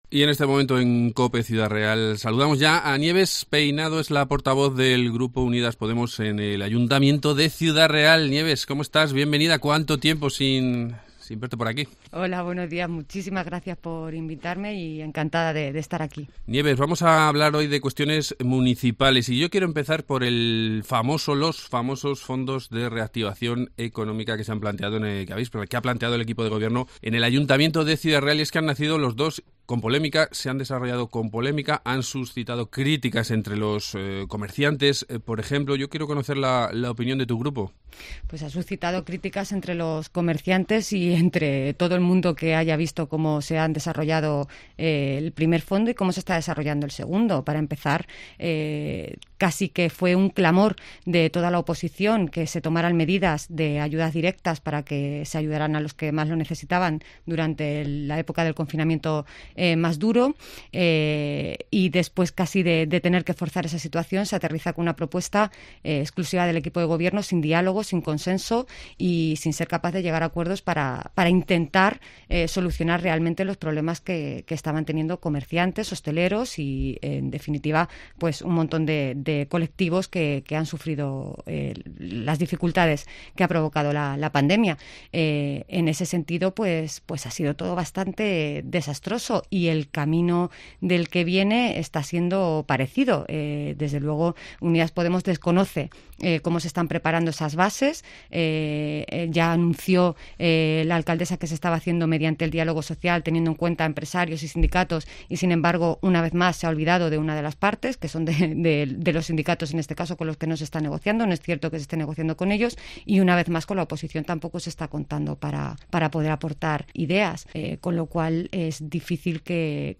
Nieves Peinado, portavoz del Grupo Municipal Unidas Podemos en el Ayuntamiento de Ciudad Real
Entrevista